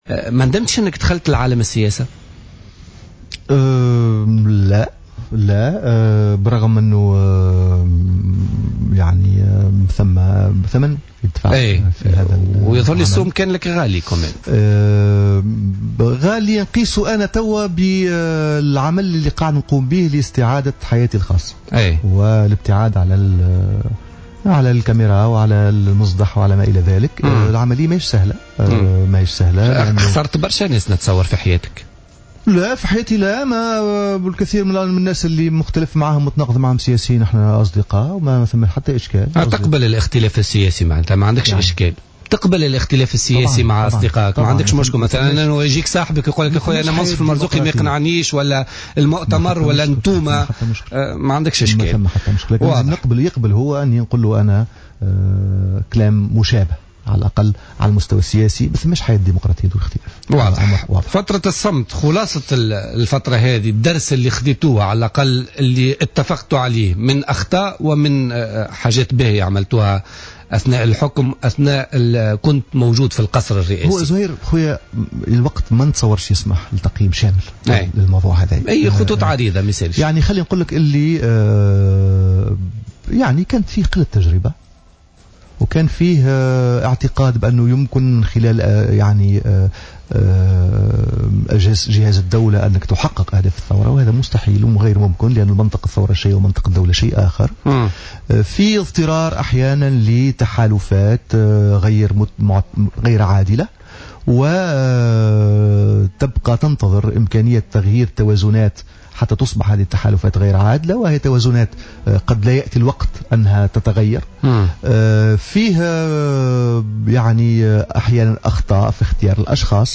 Par ailleurs, Adnene Mansar, invité de Politica ce mercredi 6 mai 2015, a déclaré que le peuple tunisien doit assumer la responsabilité et les conséquences de ses choix durant les dernières élections.